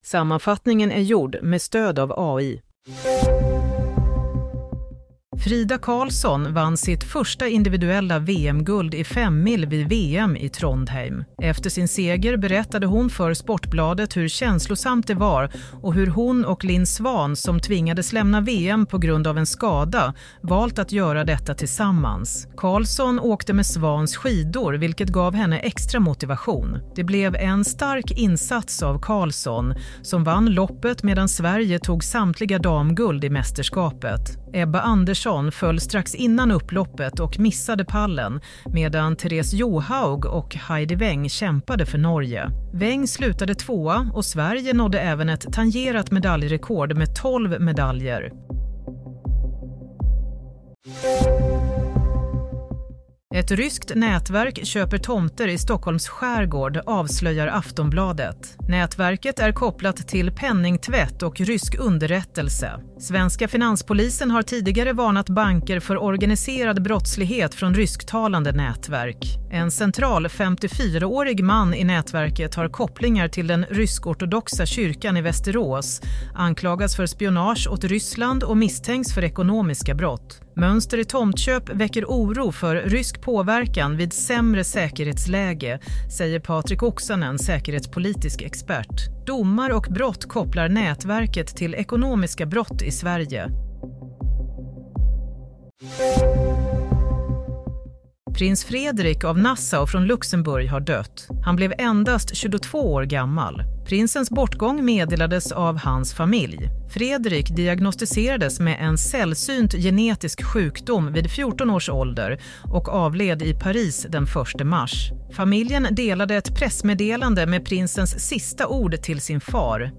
Play - Nyhetssammanfattning – 9 mars 16:00